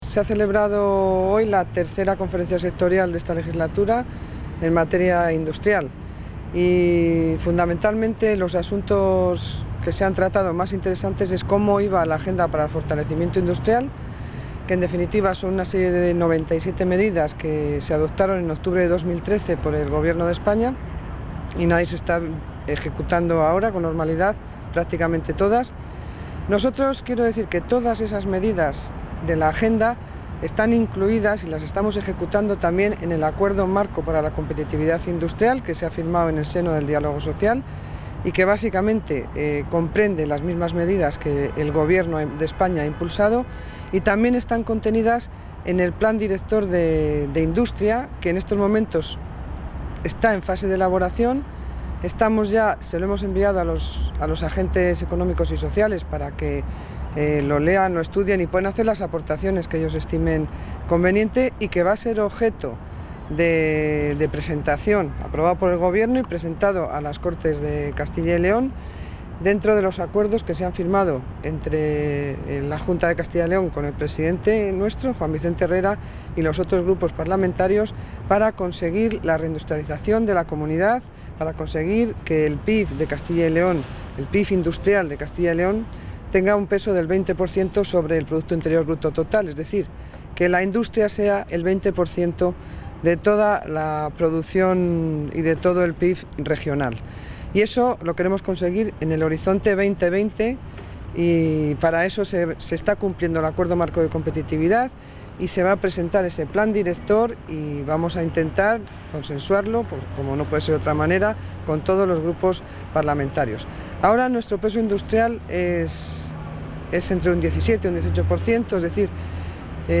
Declaraciones de la consejera de Economía y Hacienda tras la III Conferencia Sectorial de Industria y Pyme